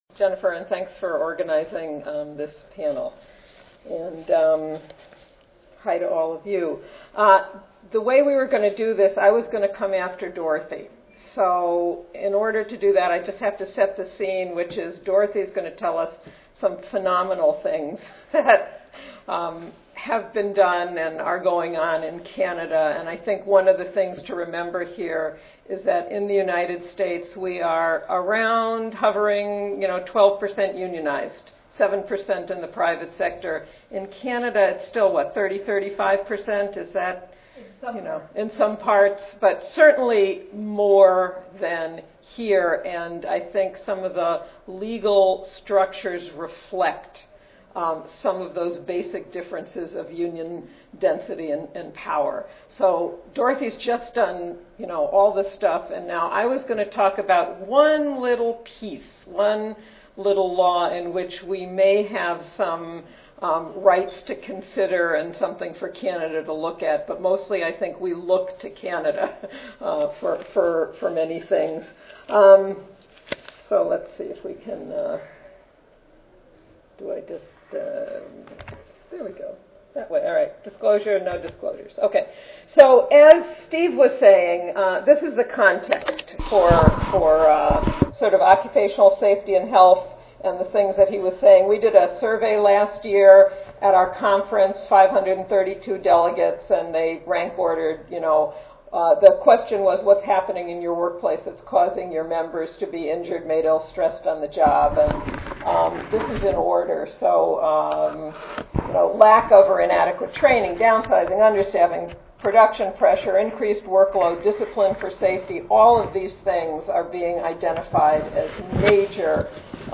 2:30 PM Panel Discussion Moderator